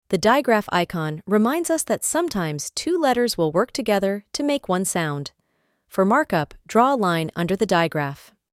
digraph-icon-lesson-AI.mp3